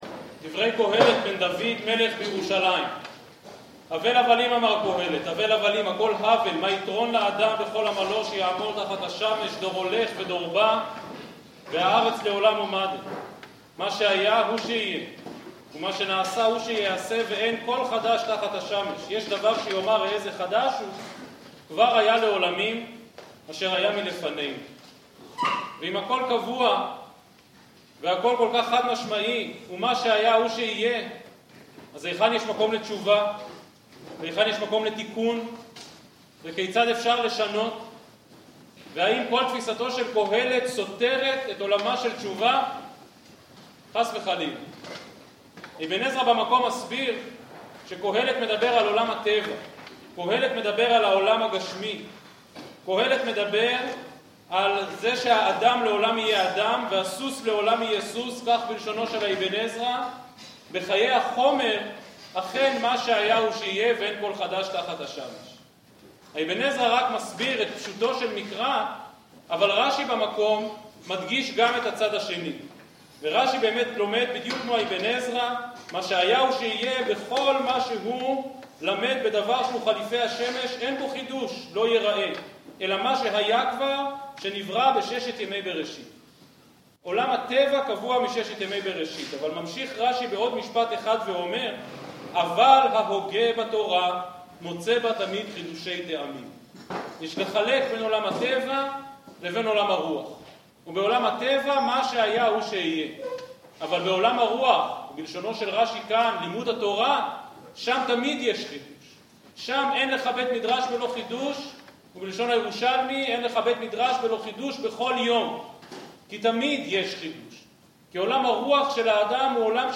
שיחה זו הועברה לפני אמירת סליחות בישיבה, מוצאי שבת נצבים תשפ"א